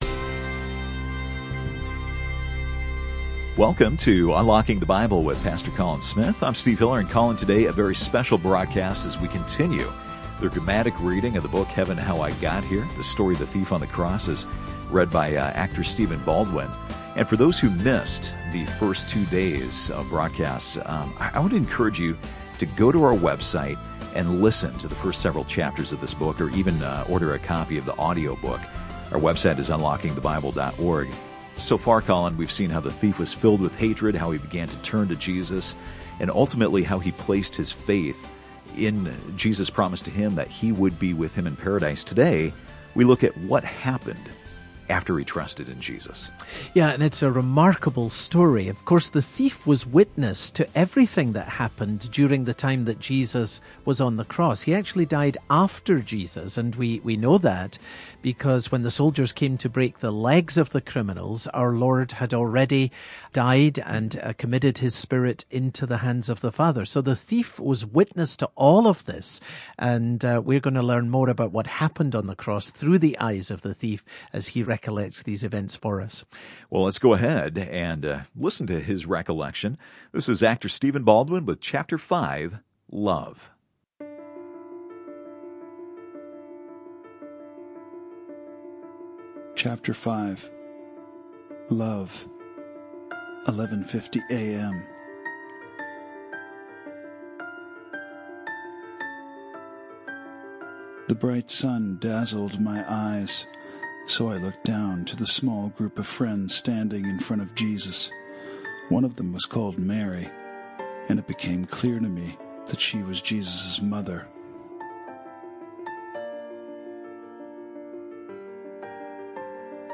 Heaven, How I Got Here is his story, told in his own words, as he looks back from Heaven on the day that changed his eternity, and the faith that can change yours. This radio broadcast features narration by actor Stephen Baldwin.